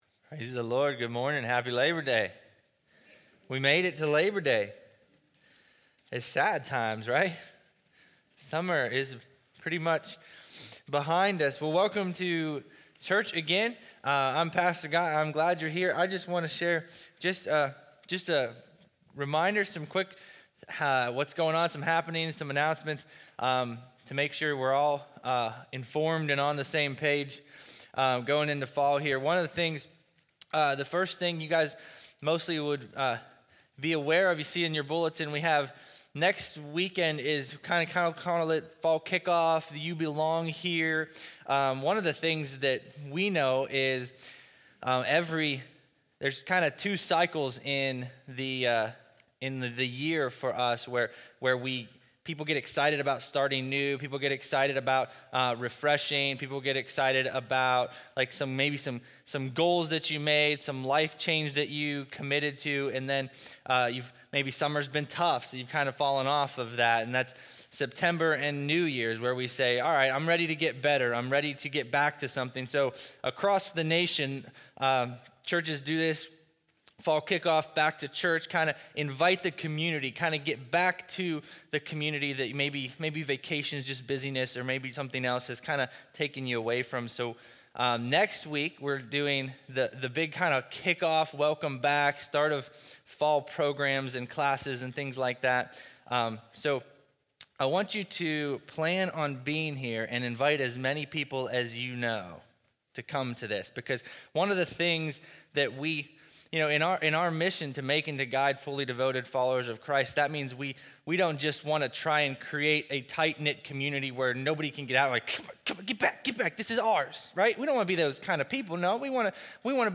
Are You All In? - One Life Church